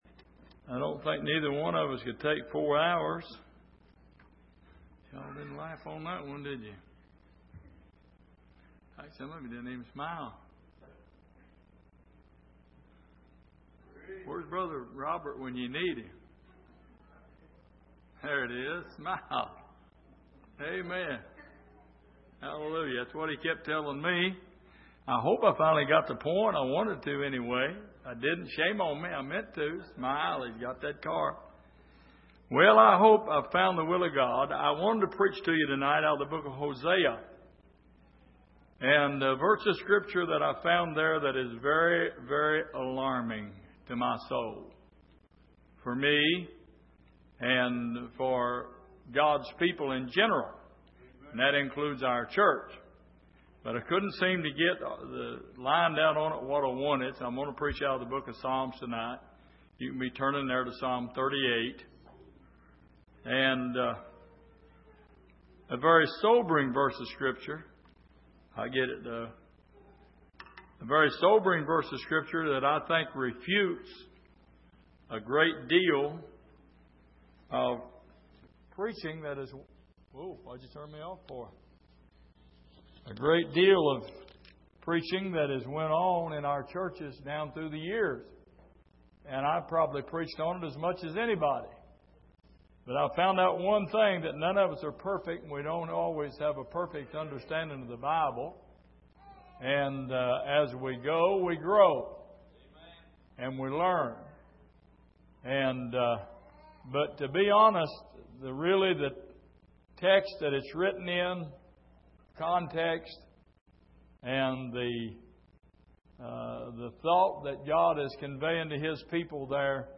Passage: Psalm 38:20 Service: Midweek